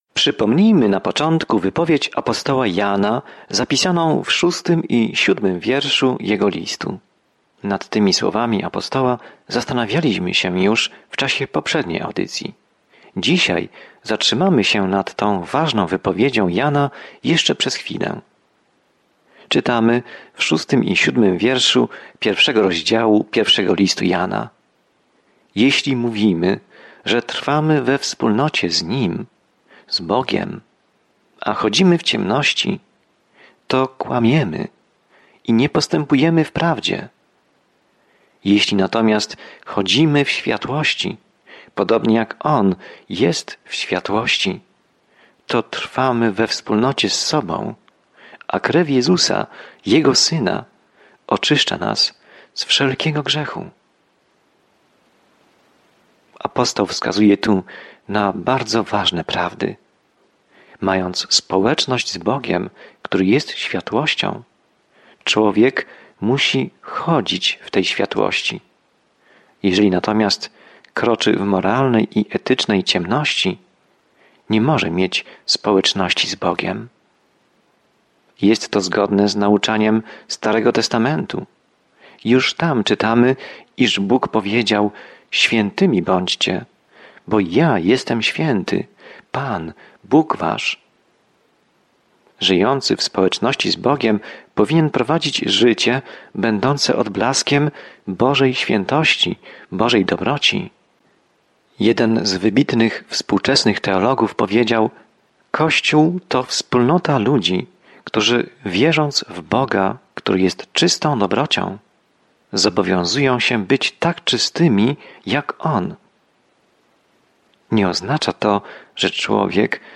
Pismo Święte 1 Jana 1:8-10 Dzień 3 Rozpocznij ten plan Dzień 5 O tym planie W pierwszym liście Jana nie ma miejsca na kompromis – albo wybieramy światło, albo ciemność, prawdę zamiast kłamstw, miłość lub nienawiść; przyjmujemy jedno lub drugie, tak jak wierzymy lub zaprzeczamy Panu Jezusowi Chrystusowi. Codziennie podróżuj po 1 Liście Jana, słuchając studium audio i czytając wybrane wersety ze słowa Bożego.